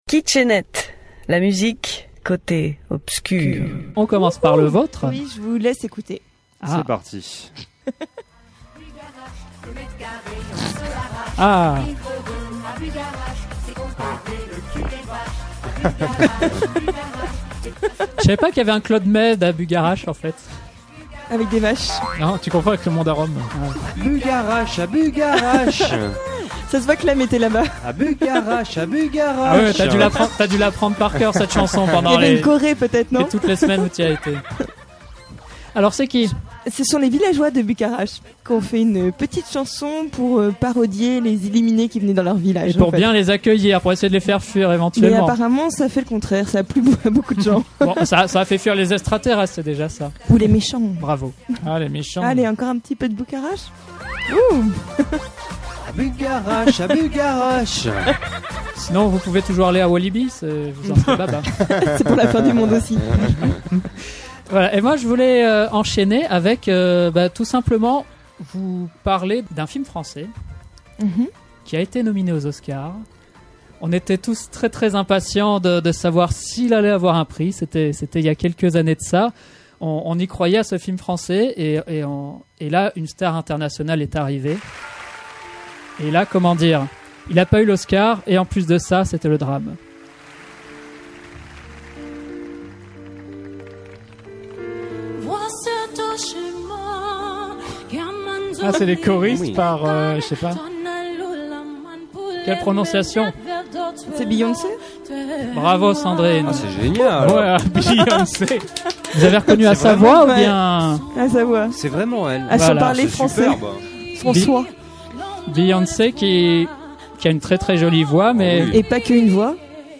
Chaque animateur de « Kitsch et Net » fait découvrir en live à ses petits camarades des extraits musicaux dits « HARDkitschs » (voir ici la définition), qui ne seront pas diffusés en entier…
Savourez à volonté tous ces happenings pleins de surprises, de délires et surtout de gros éclats de rire, et retrouvez aussi des informations sur les chanteurs diffusés…
Attention à vos oreilles…